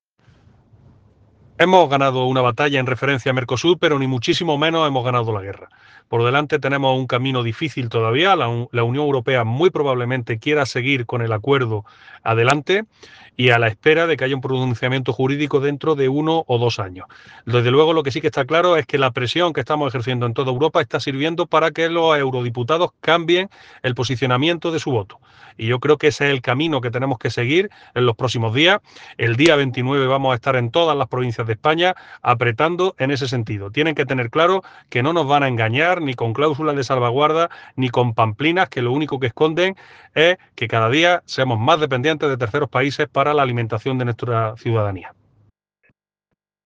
**Declaraciones